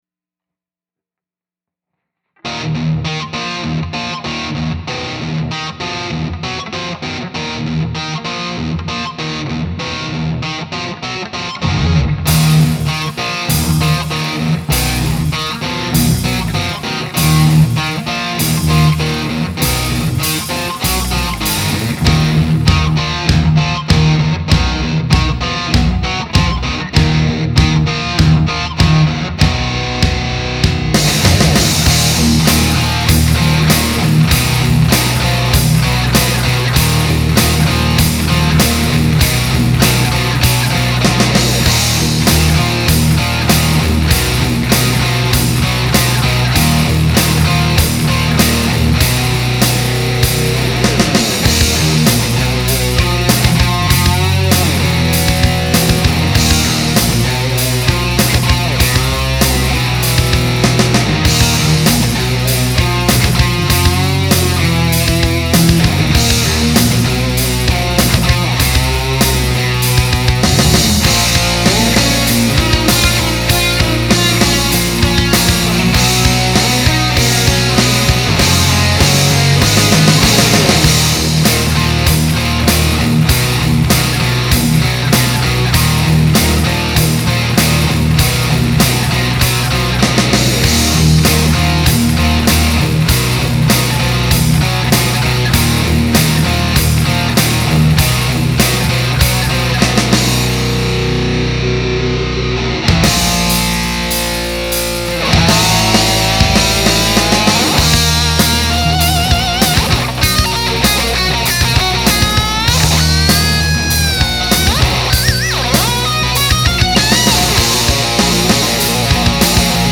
Guitars
Bass
Tuning = Eb, Ab, Db, Gb, Bb, Eb,
*This was the setup used for the entire track except the solo which adds the Tube Screamer Overdrive.
Guitars 1 & 2 play - Guitar 2 enters after 1 go around of the riff by guitar 1.
Played with thumb and index finger NOT a PICK..
This is the main theme riff from our intro but this time we use a pick.